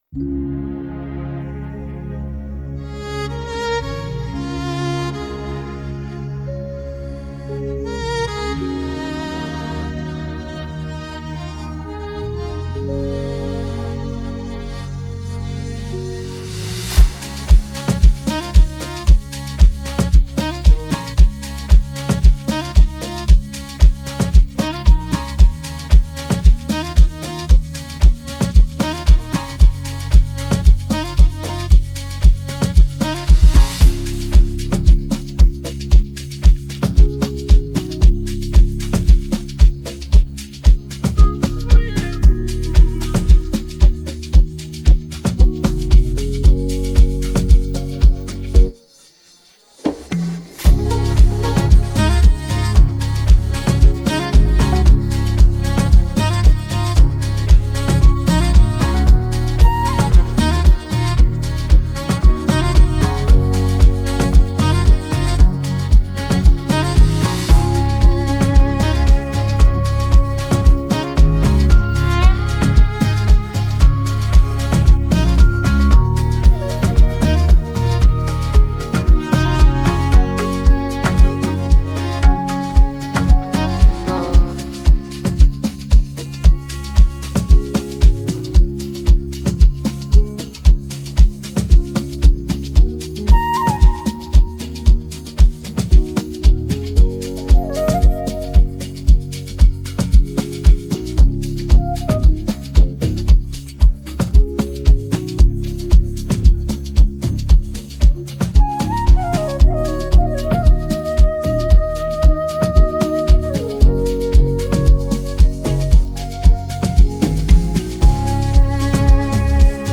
AfrobeatsAmapaino